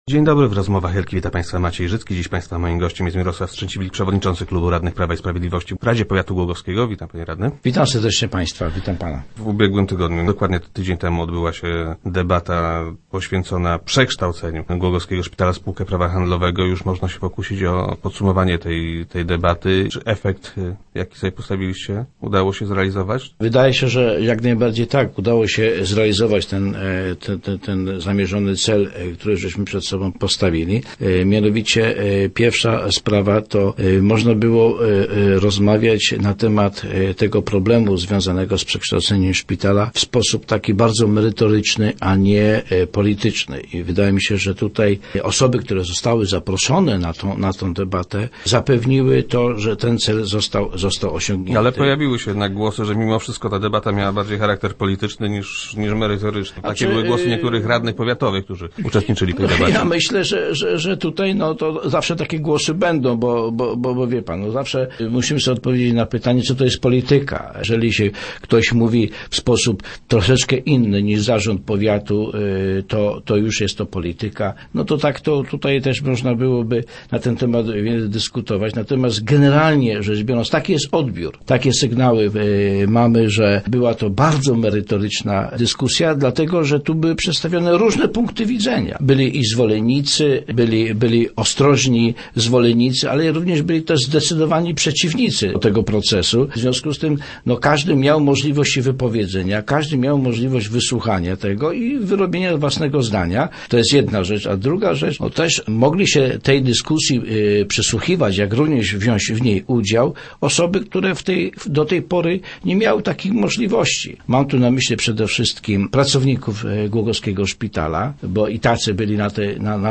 Start arrow Rozmowy Elki arrow Strzęciwilk: Zakładany cel osiągnęliśmy
0107_strzeciwilk_do_rozmw.jpgUbiegłotygodniowa debata na temat ewentualnego przekształcenia głogowskiego szpitala w spółkę prawa handlowego, nie rozwiała wątpliwości z tym związanych. - Na pewno jednak była bardzo przydatna – twierdzi Mirosław Strzęciwilk, przewodniczący klubu radnych powiatowych PiS, który był gościem Rozmów Elki.